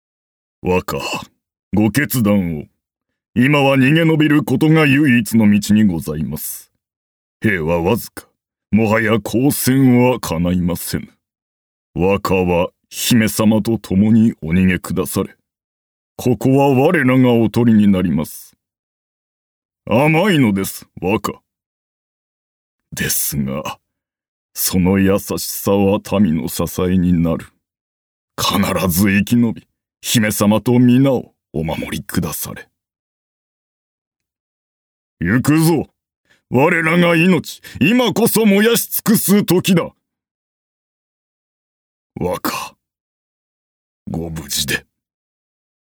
男性声優・キャラクターナビゲーション
その他の性格
（老将）